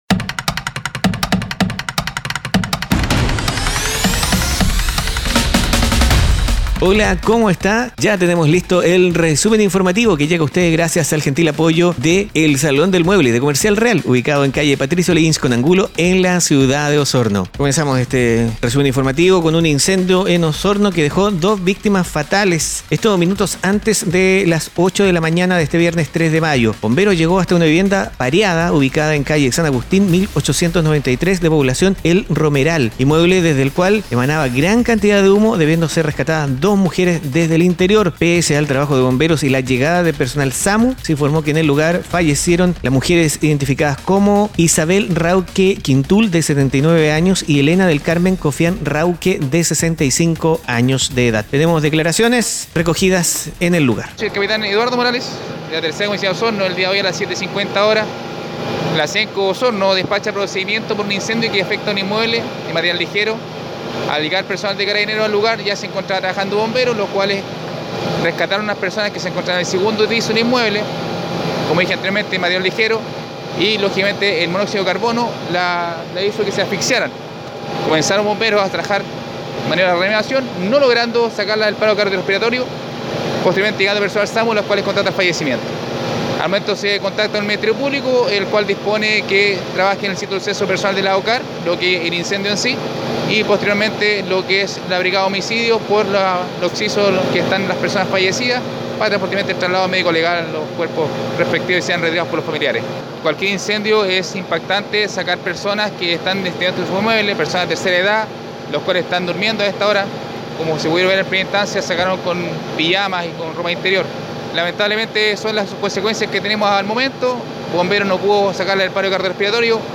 Resumen Informativo - Viernes 3 de mayo 2019